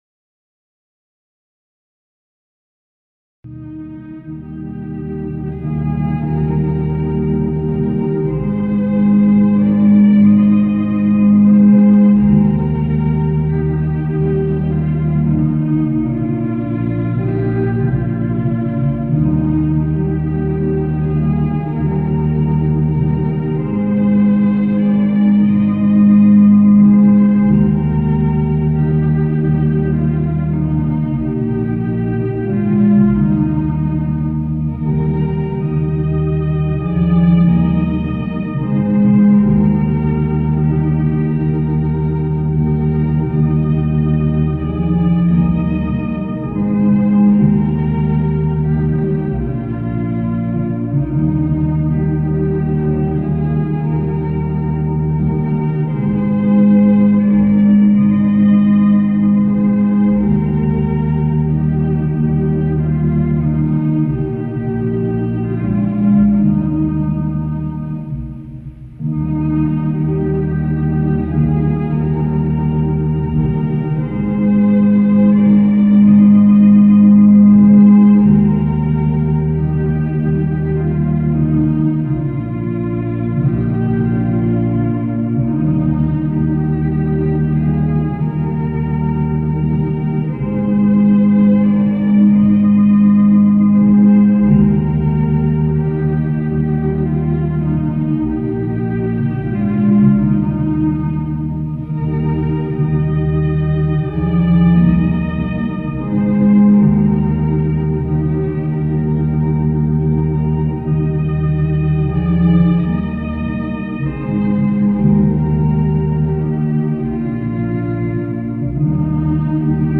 INNI ROSACROCIANI
INNO DI APERTURA